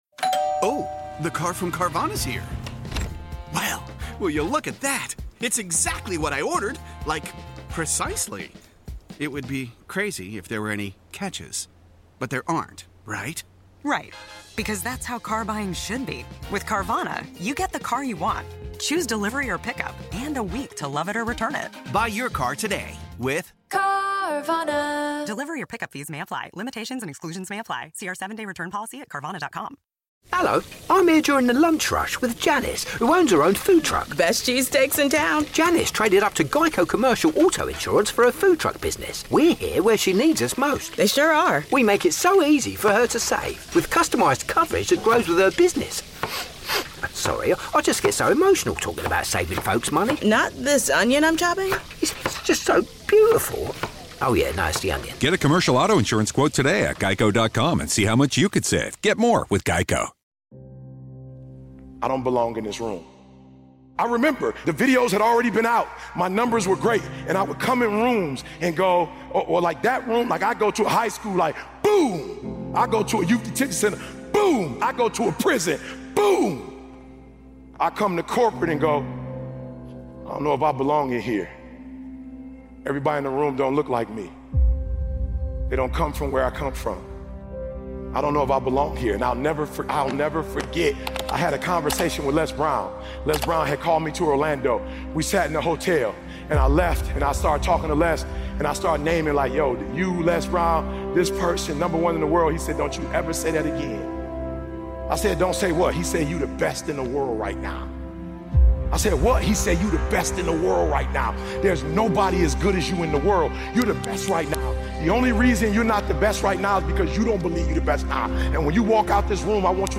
Powerful Motivational Speech Video featuring Eric Thomas.
Speaker: Eric Thomas